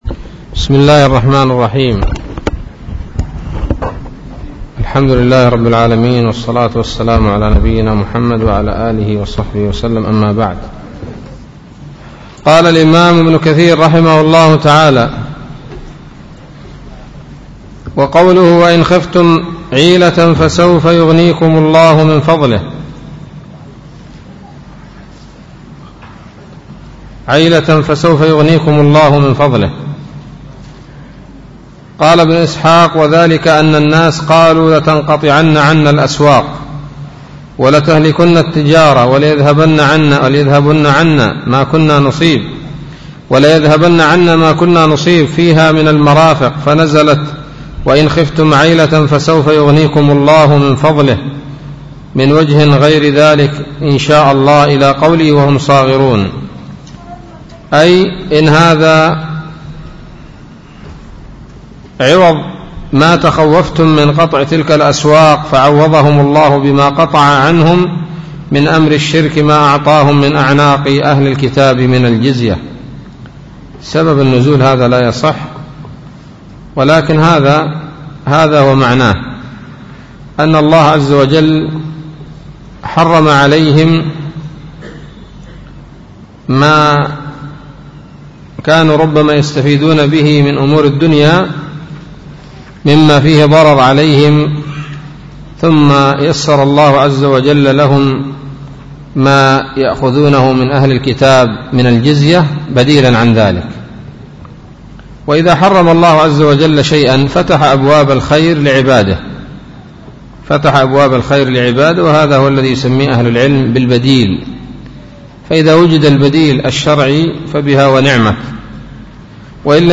الدرس الثاني عشر من سورة التوبة من تفسير ابن كثير رحمه الله تعالى